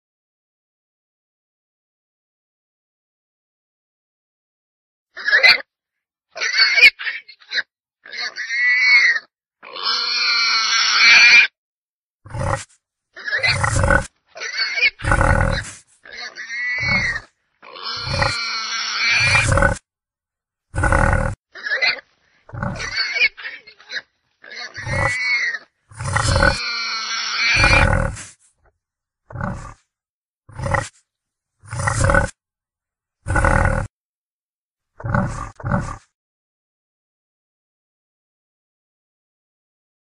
Sonido del Jabalí.Sonidos cortos de animales.mp3